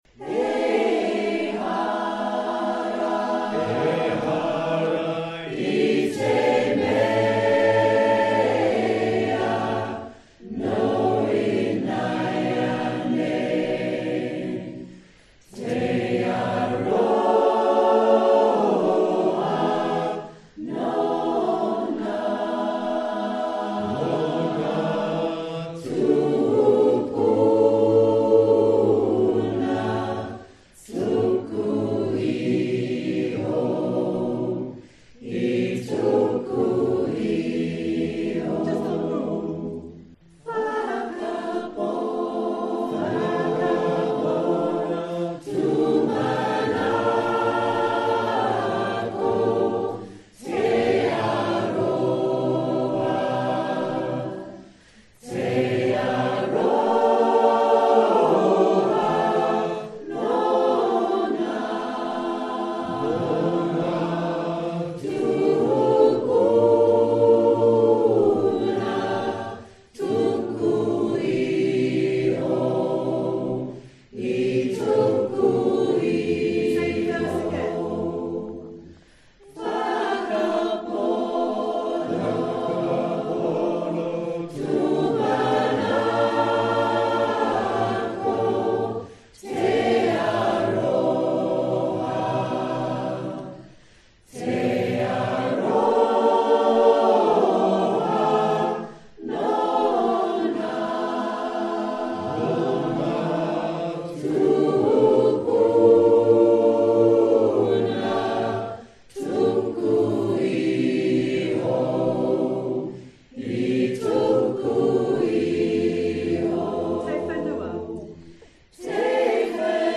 Here’s a recording of a song in a mystery language.
The pronunciation seems somewhat “anglified”.